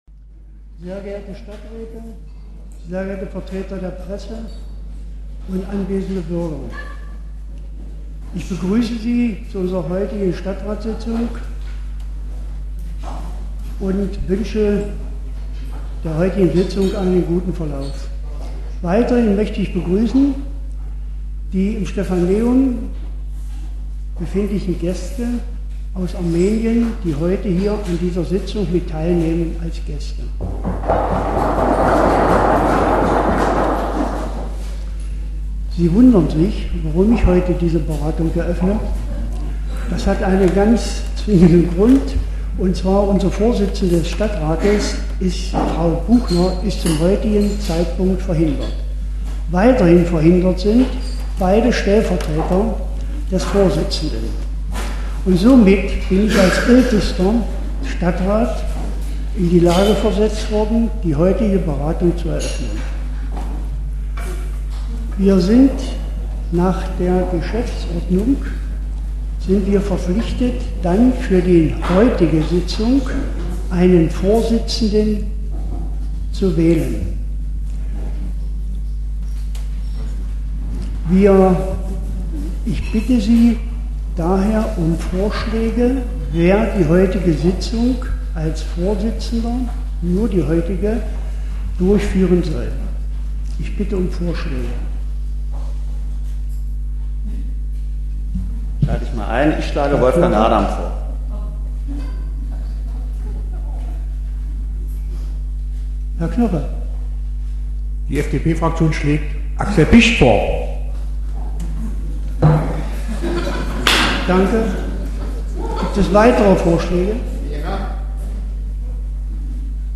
Stadtratssitzung in Aschersleben
radio hbw strahlt regelmäßig die Sitzungen des Ascherslebener Stadtrats aus. Diesmal wurden die Wahlen der Ortsbürgermeister bestätigt, es ging um den Bau einer Mensa mit zwei Klassenräumen an der Grundschule Staßfurter Höhe, um die papierlose Stadtratsarbeit und um etliche Anträge der Fraktion Grüne/SPD, etwa zu Baumpflanzungen und zur Schaffung von Behindertenparkplätzen.